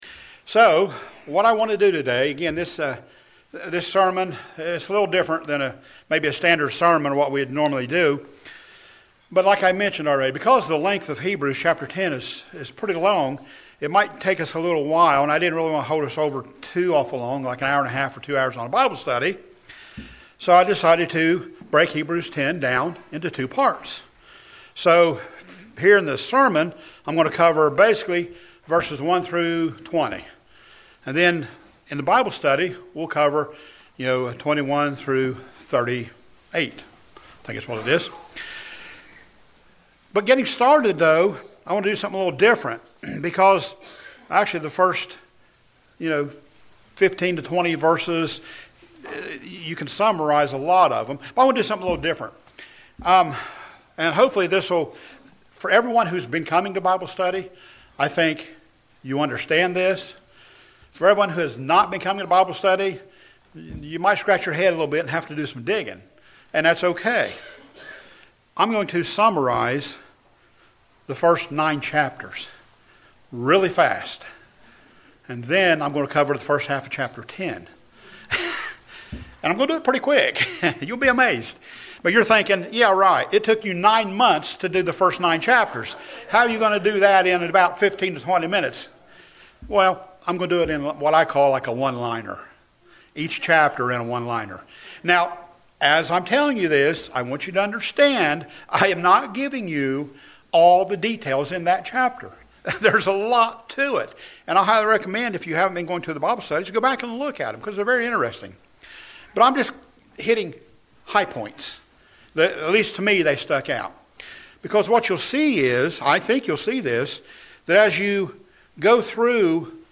In this sermon we will cover verses 1-20. We will then come back for Bible Study and cover verses 21-38.